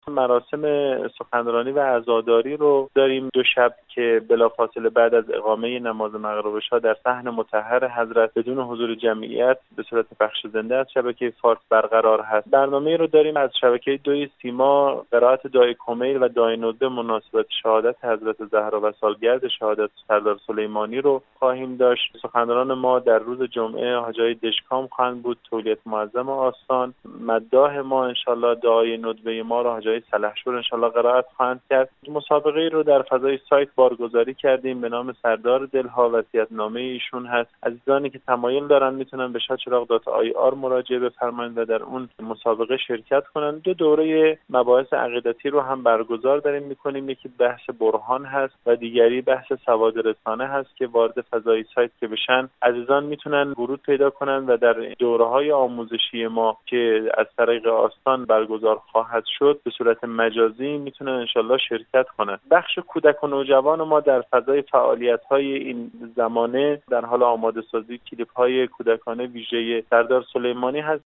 وی در گفتگو با خبر رادیو زیارت افزود: